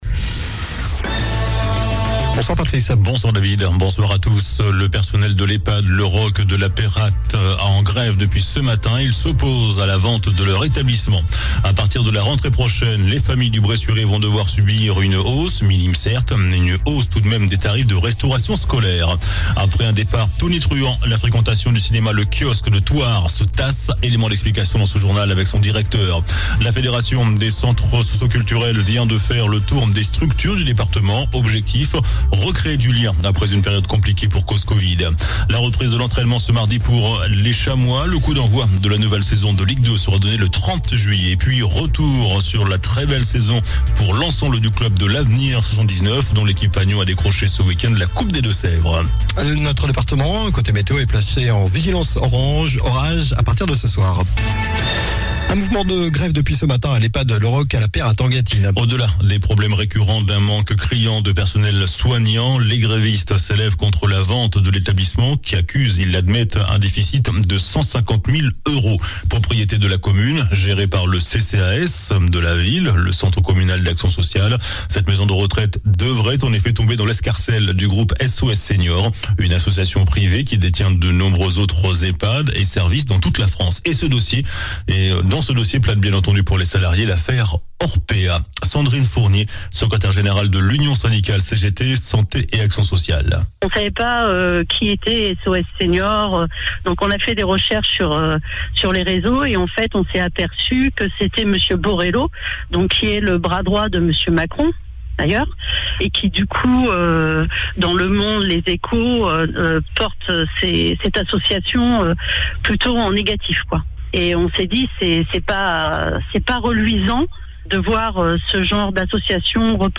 JOURNAL DU MARDI 21 JUIN ( SOIR )